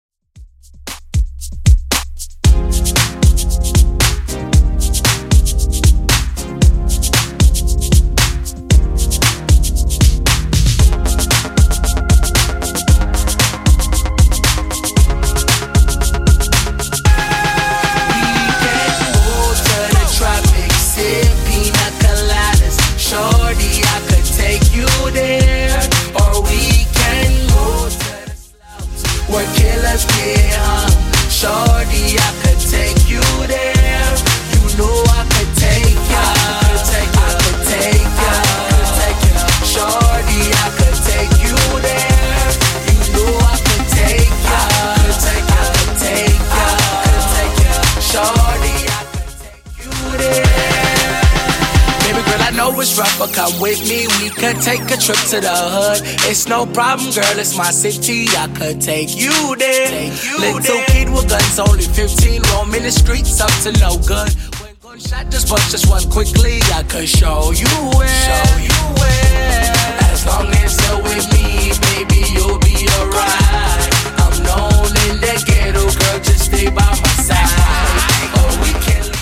Genre: 80's
BPM: 135